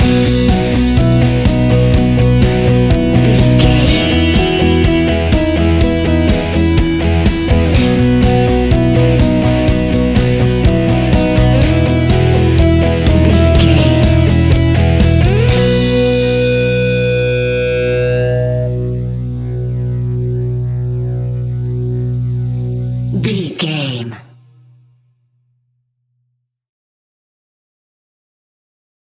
Uplifting
Ionian/Major
indie pop
fun
energetic
cheesy
instrumentals
guitars
bass
drums
piano
organ